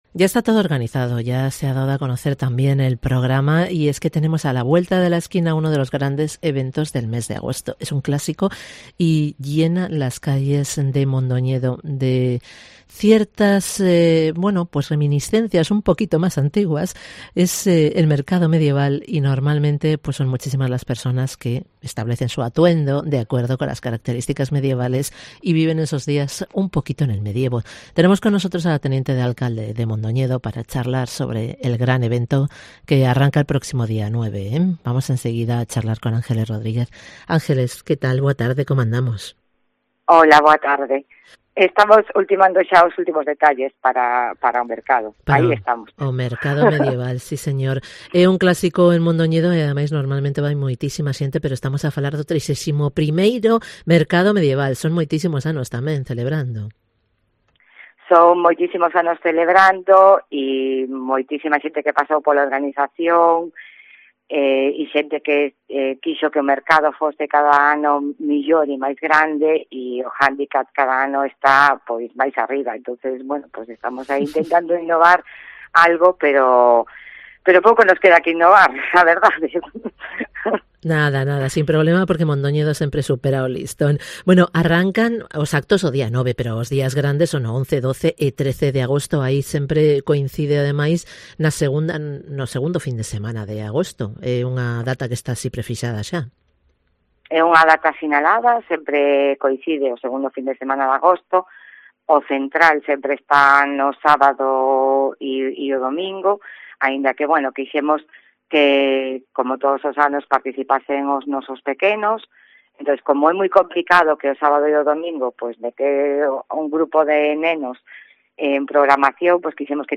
Entrevista con la teniente de alcalde de Mondoñedo, Ángeles Rodríguez